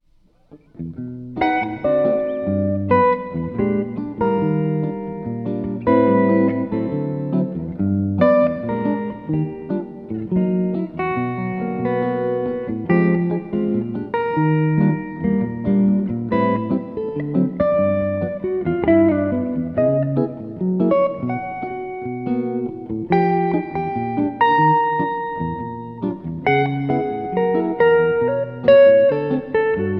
Guitar